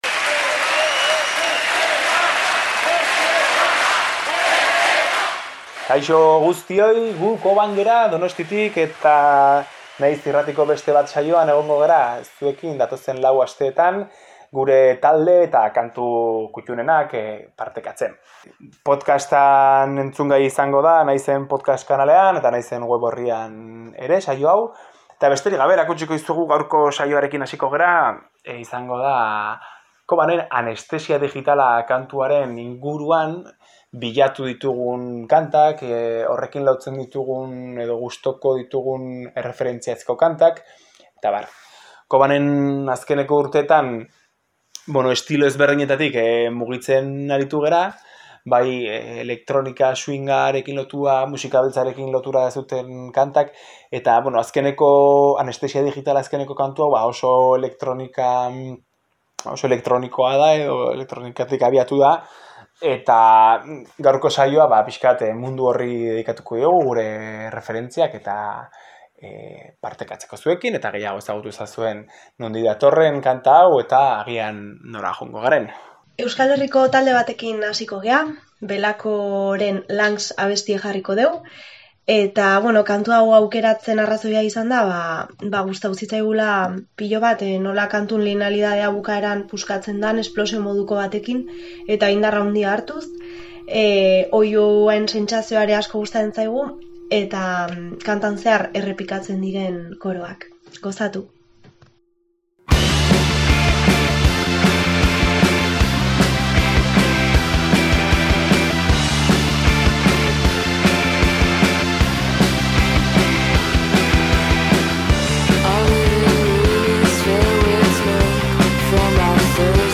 Naiz irratiko saio musikala. Euskal Herriko musikariek txandaka gidatutako saioa. Kantu aukeraketa bat.